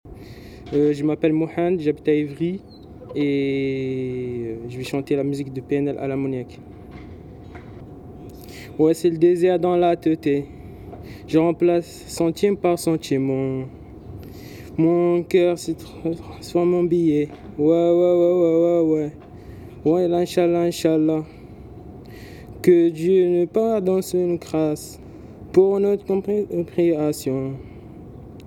rap en français